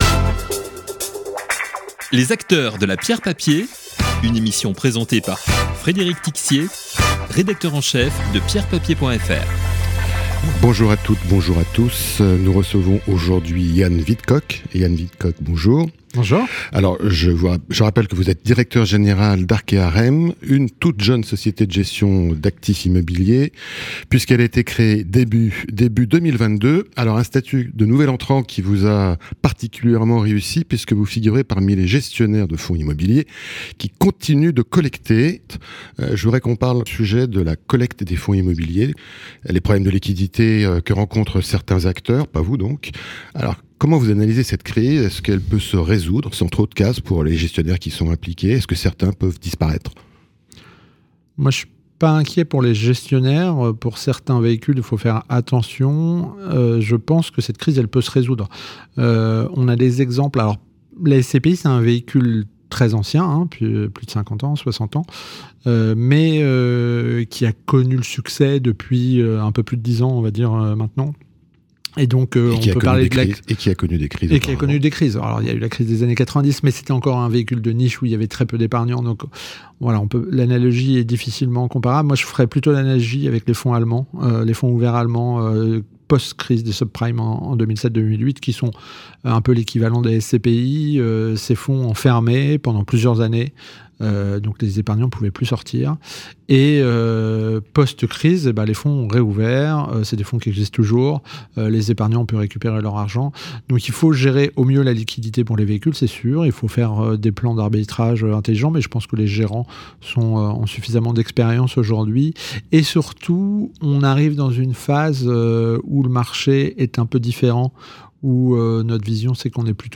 Podcast d'expert
Et évoque le sujet de la collecte, qui manque cruellement à certaines SCPI. Interview.